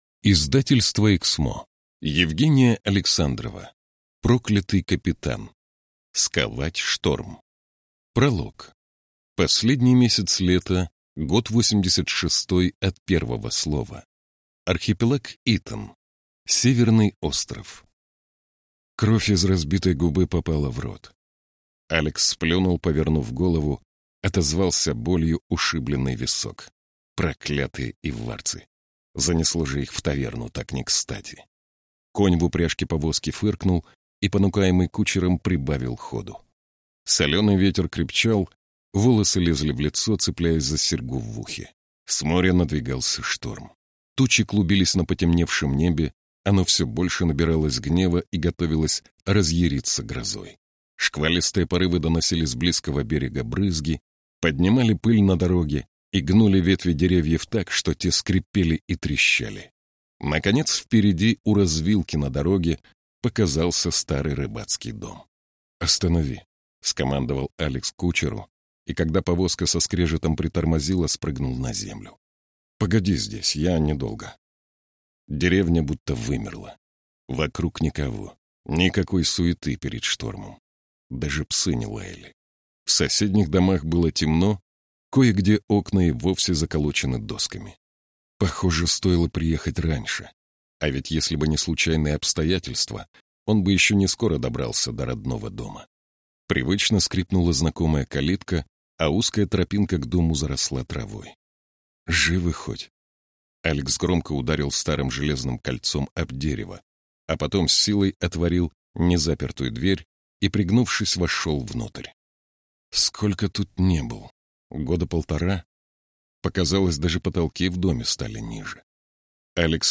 Аудиокнига Проклятый капитан. Сковать шторм | Библиотека аудиокниг